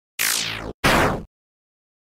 coin.wav